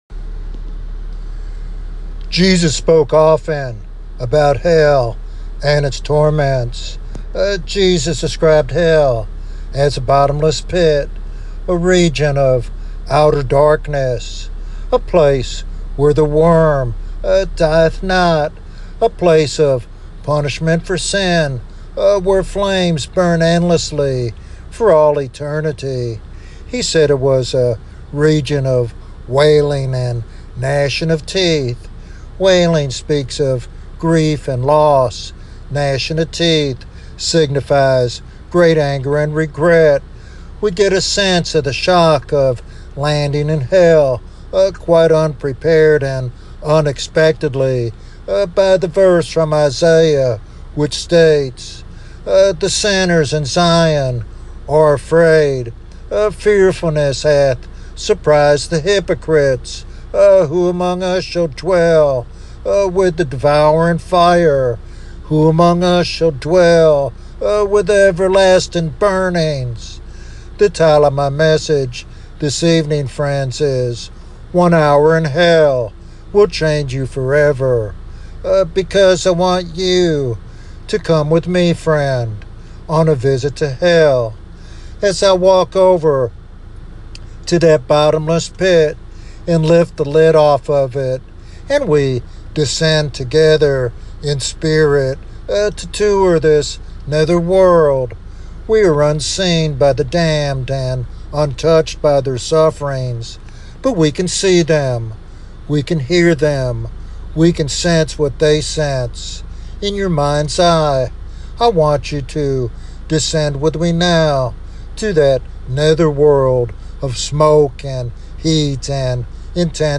The sermon presents hell as a literal place of eternal punishment, based on Jesus’ own teachings.